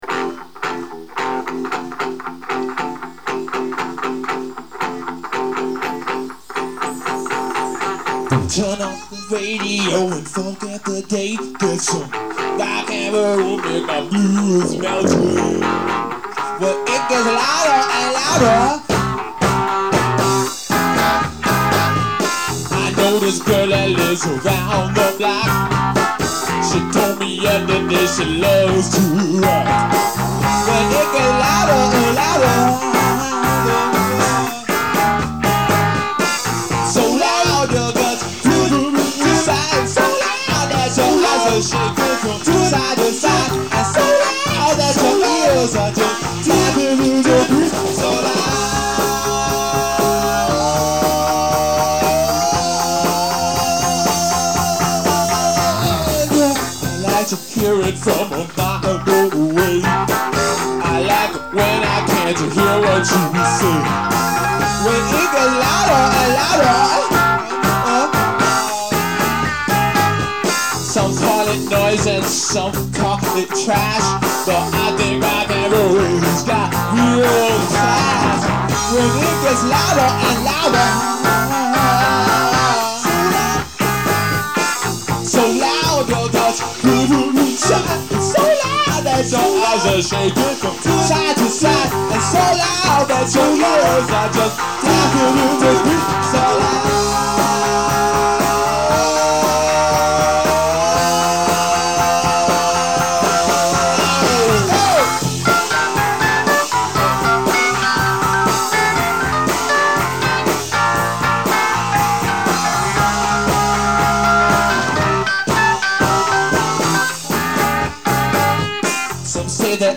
Guitar & Vocals
Keyboards & Vocals